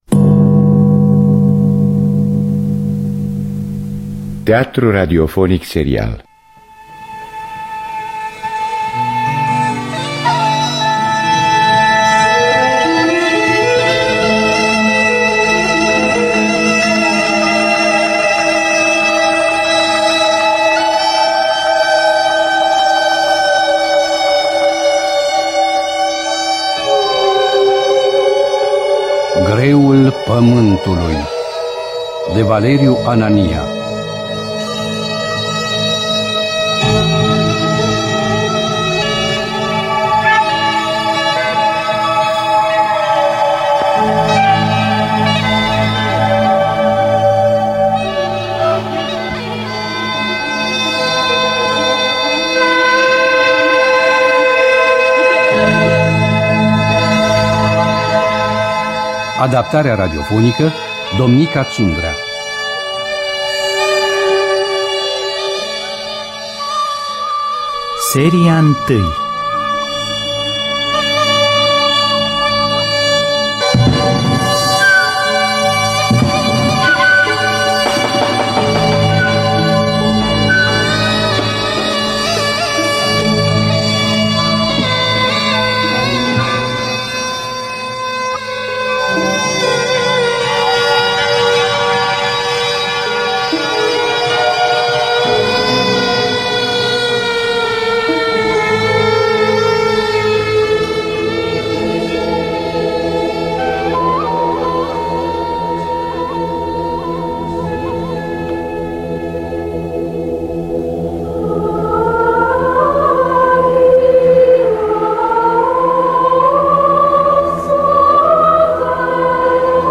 Valeriu Anania – Greul Pamantului (2002) – Episodul 1 – Teatru Radiofonic Online